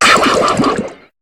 Cri de Denticrisse dans Pokémon HOME.